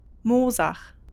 Moosach (German pronunciation: [ˈmoːzax]